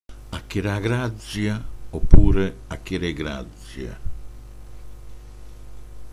Si pronunciava tutta d’un fiato anche nella versione acchéregrazzje (a-chére-e-grazzje)